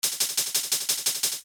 We can improve the sound by making the velocities alternate between loud and soft.